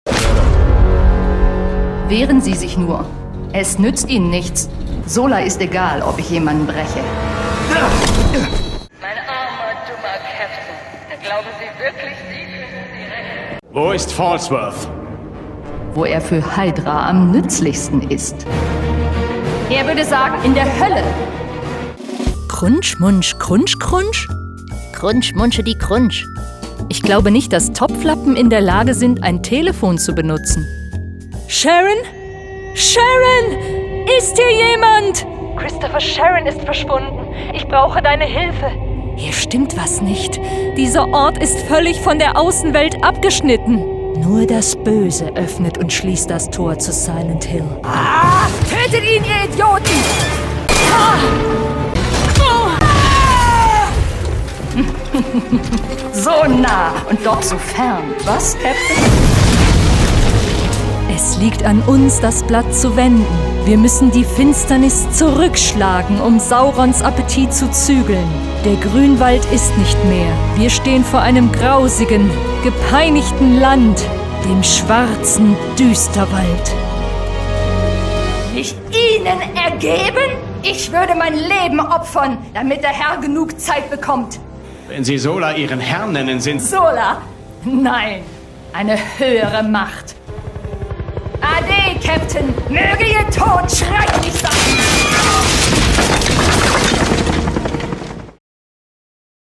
Female
Approachable, Assured, Authoritative, Bright, Character, Confident, Conversational, Cool, Corporate, Deep, Engaging, Friendly, Gravitas, Natural, Reassuring, Sarcastic, Smooth, Soft, Warm, Witty, Versatile
German (native), English with slight European accent
Voice reels
Microphone: Neumann TLM 103